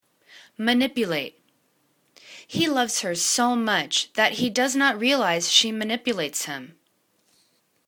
ma.nip.u.late    /mə'nipjəla:t/    v